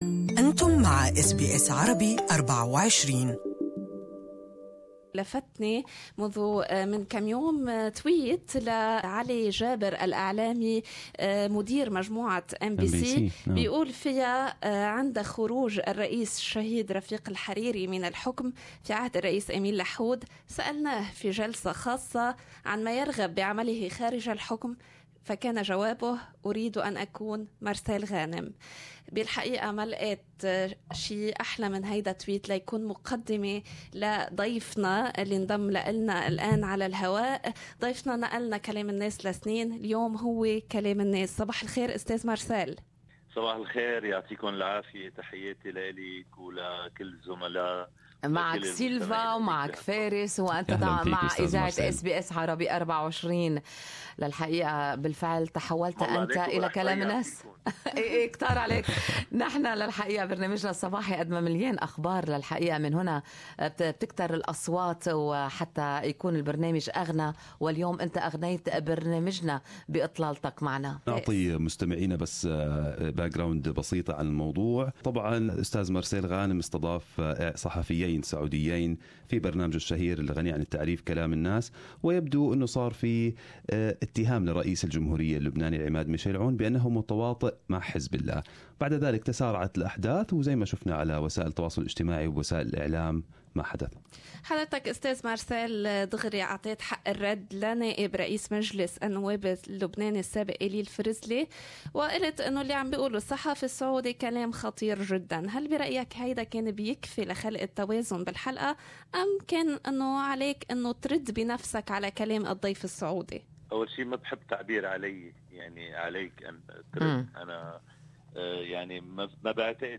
الإعلامي اللبناني ومقدّم برنامج "كلام الناس" مارسيل غانم تحدّث عبر برنامج Good Morning Australia عن الضجة الأخيرة التي أثارها طلب المثول أمام القضاء، وذلك على خلفيّة حلقة من برنامجه استضاف فيها إعلاميّين سعوديّين.
إستمعوا إلى اللقاء الكامل من خلال الضغط على التدوين الصوتي أعلاه شارك